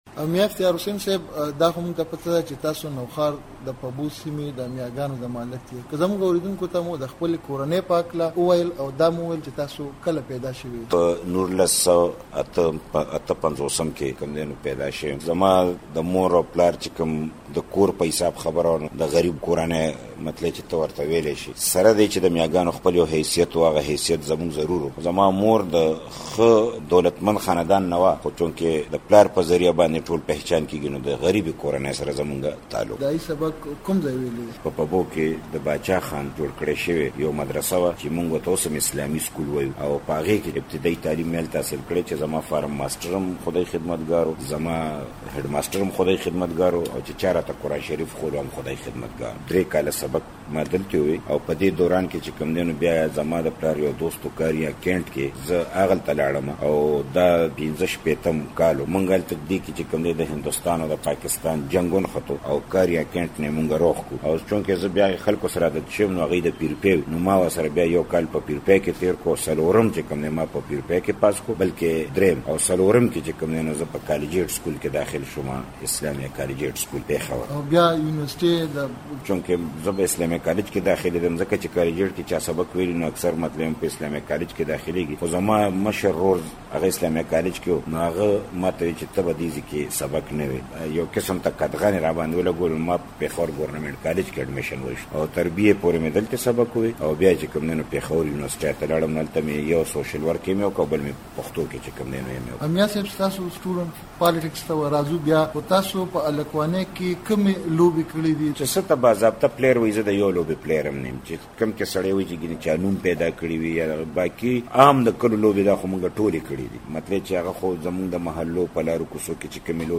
د مشال مرکه مو دا اونۍ د خیبر پښتونخوا د اطلاعاتو وزیر میا افتخارحسین سره کړې .